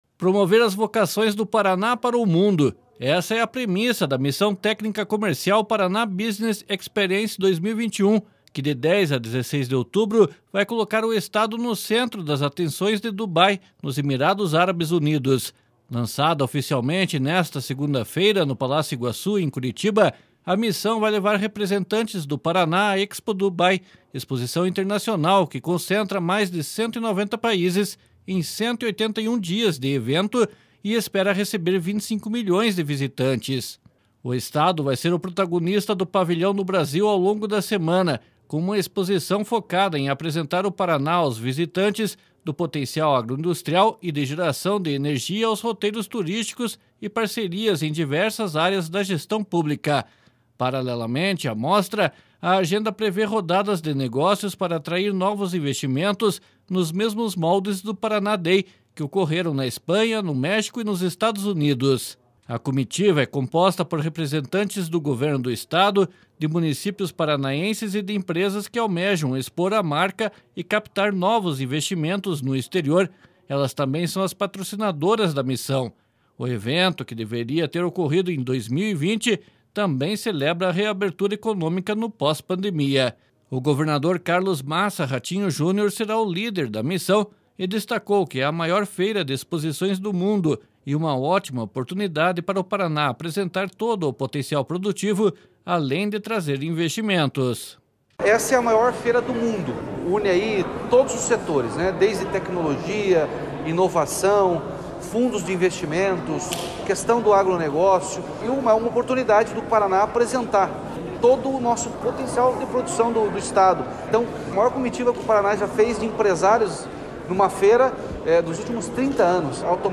//SONORA RATINHO JUNIOR//
//SONORA ELIZABETH SCHIMIDT//